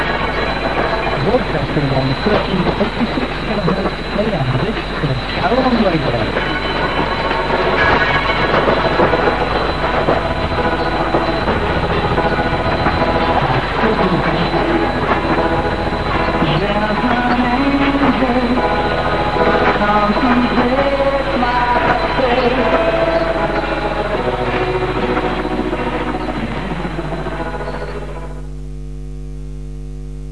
Here are some loggings of MW/SW-stations heard in Paimio (not all stations listed here)
mp3  Low power UK-station (1 Watt !!)